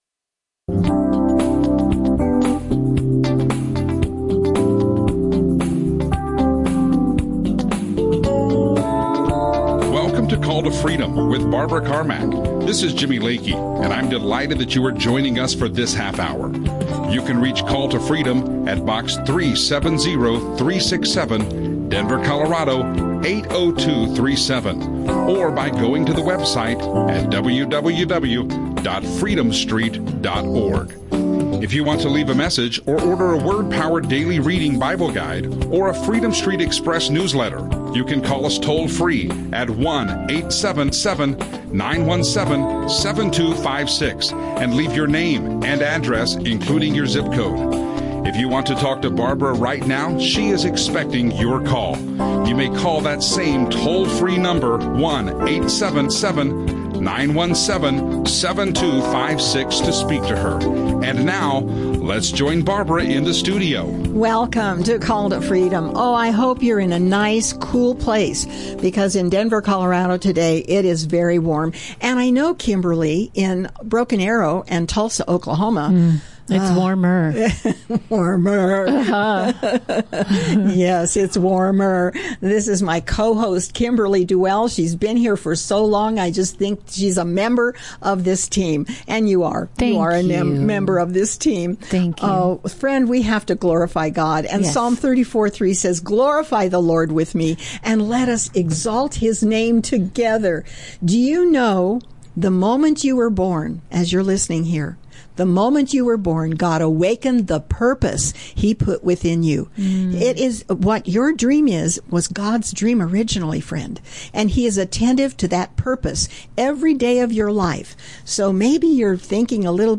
Call to Freedom Christian radio
radio show